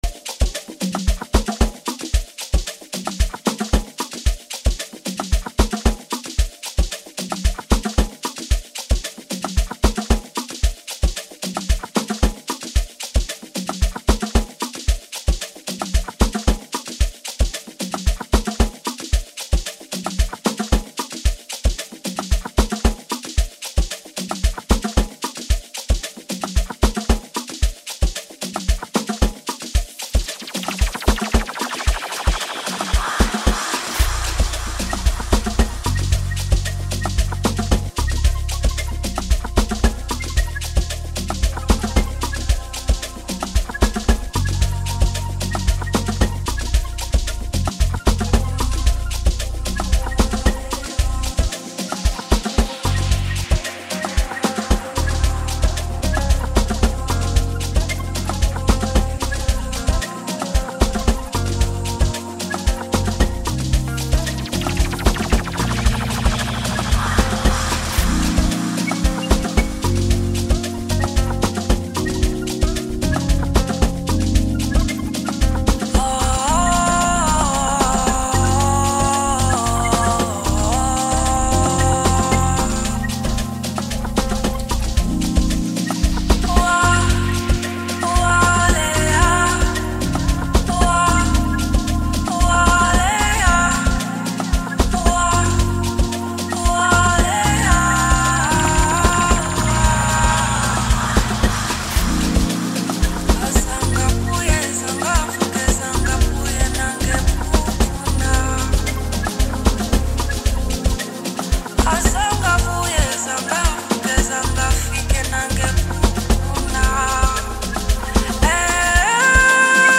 Home » Amapiano » DJ Mix » Hip Hop
South African singer-songsmith